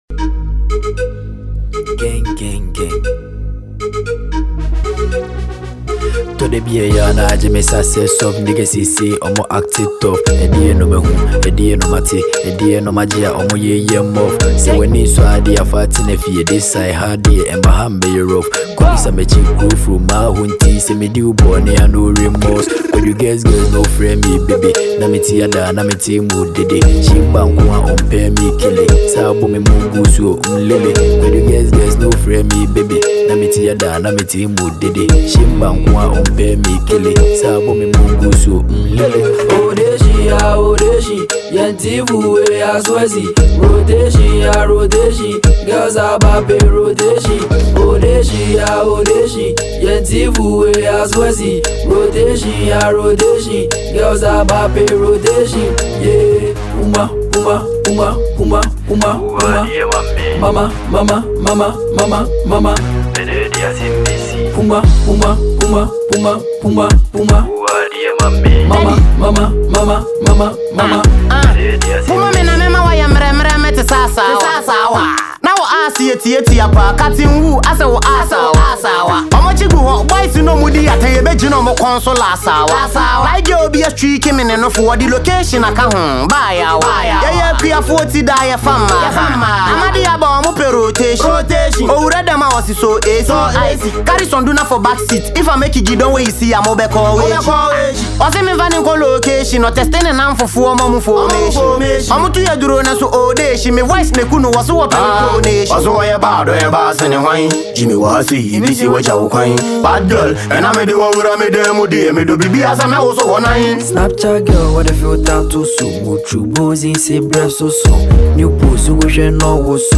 a Ghanaian asakaa rapper